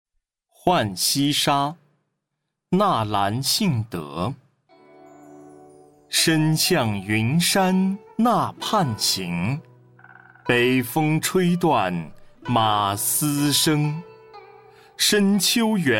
课文朗读 九语下第三单元课外古诗词诵读 浣溪沙（素材）